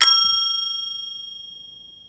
Spyral Vertical forgófejes csengő, 53 mm, fekete
Bármerre forgatod, klasszikus csengető hangot ad.  Hosszú lecsengés, erős hang